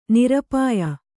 ♪ nirapāya